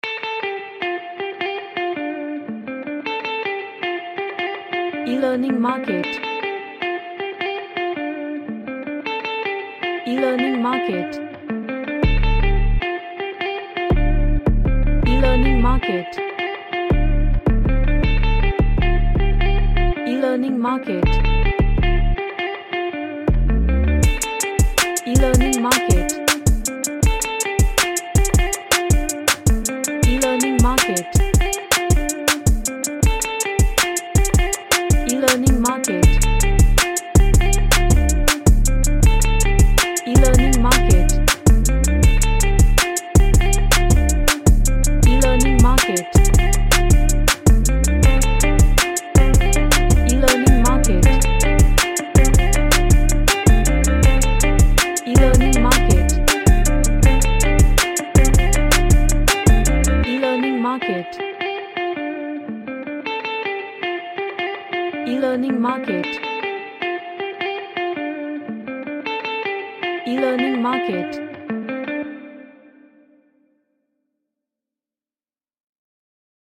A chill track with energetic Vibe
Chill Out